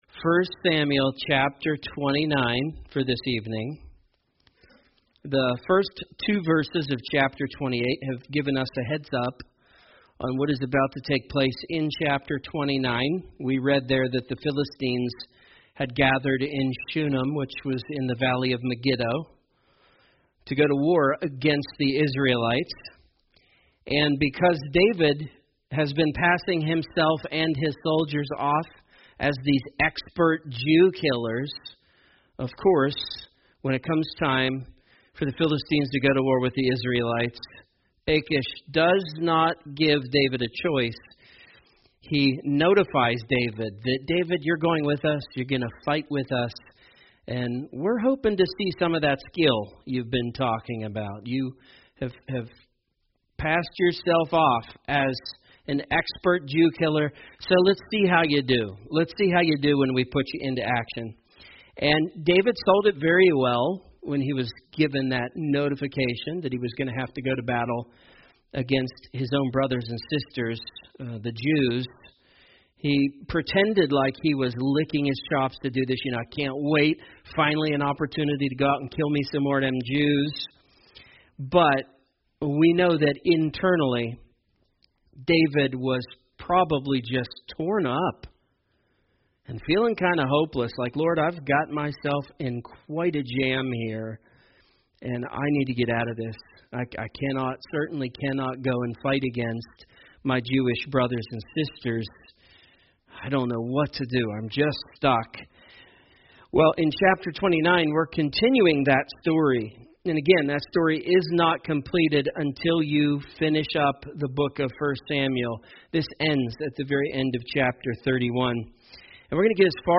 A message from the topics "The Book of 1 Samuel."